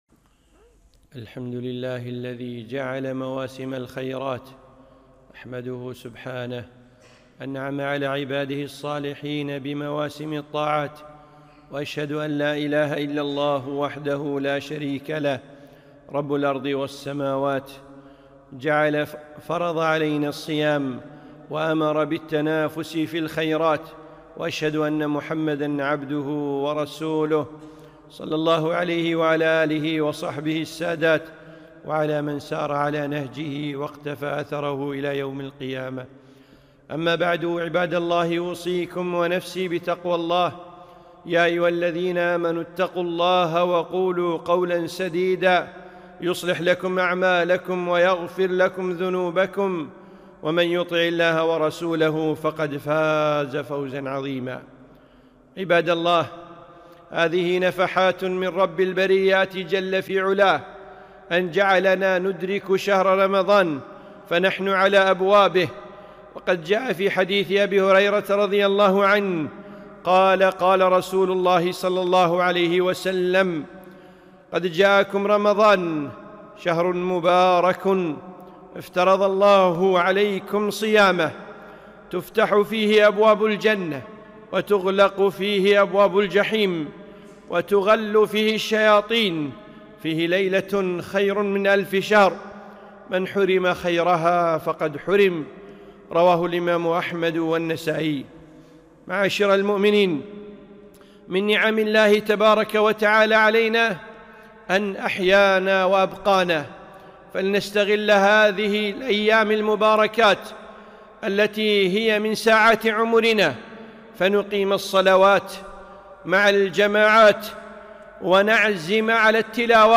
خطبة - نفحات ووقفات